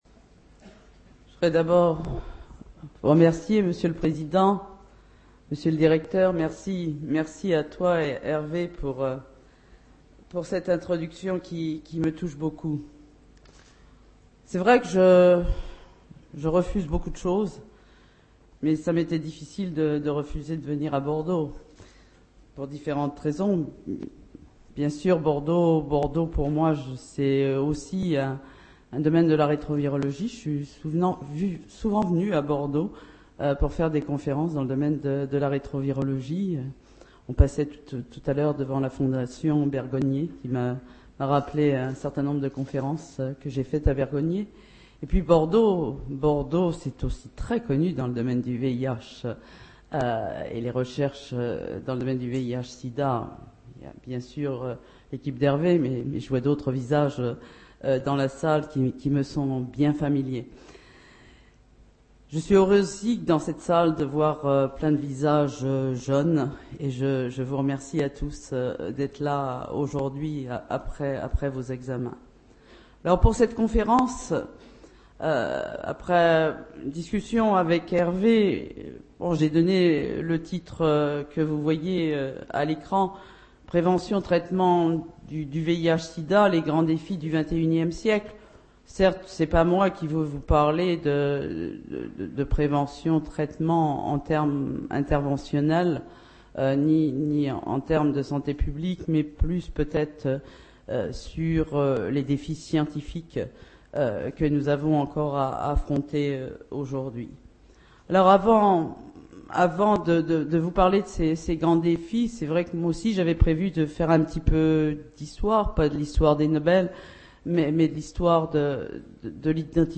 Conférence de Françoise Barré-Sinoussi, corécipiendaire du Prix Nobel de médecine 2008, pour la découverte du virus responsable du Sida.